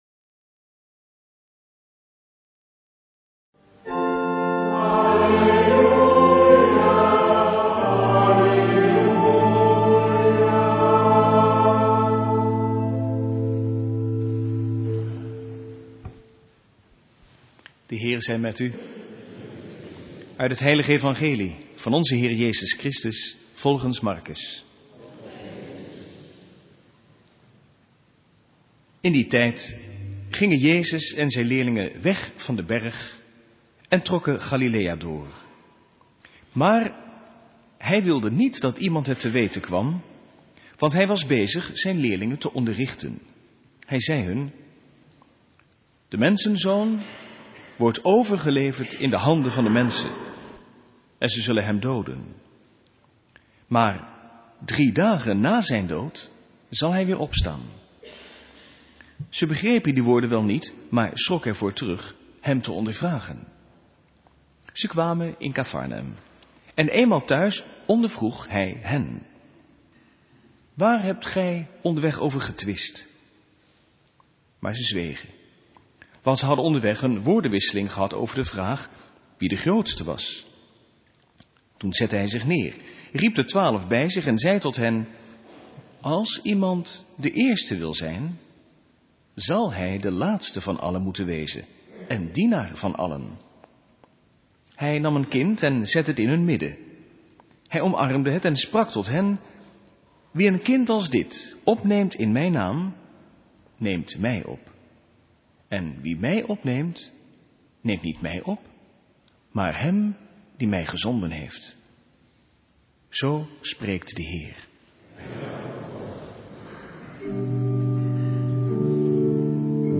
Lezingen
Eucharistieviering beluisteren vanuit de Jozefkerk te Wassenaar (MP3)